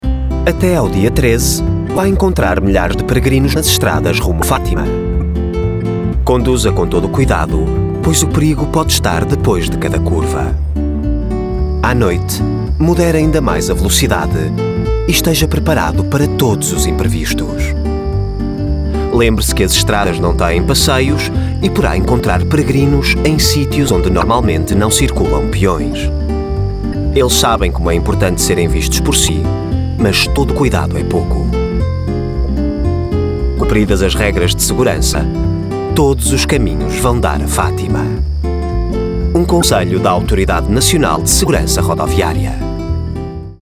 Numa época em que milhares de peregrinos caminham em direção a Fátima, a ANSR recorda os cuidados a ter nas estradas portuguesas. Materiais Disponíveis:  Cartaz I Cartaz II spot rádio vídeo: Conselhos para os peregrinos vídeo : Conselhos para os automobilistas